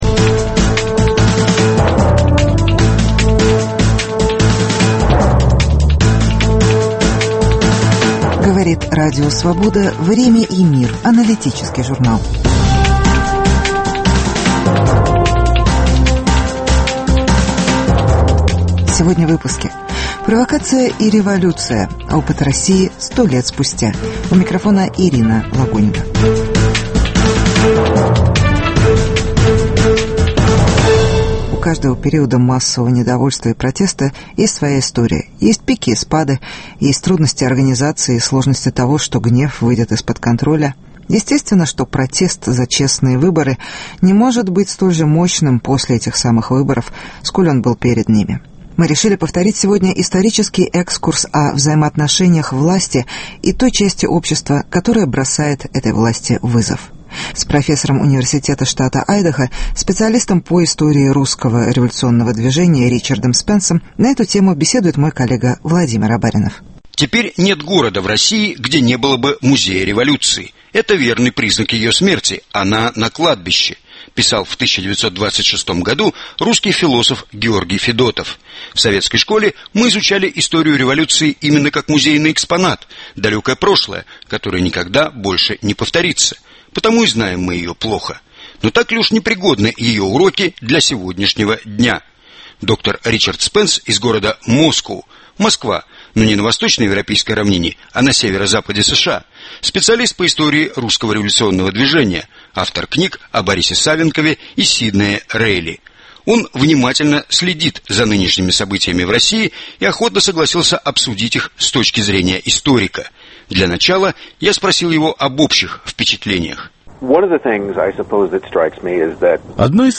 Провокация и революция: опыт России 100 лет спустя (повтор интервью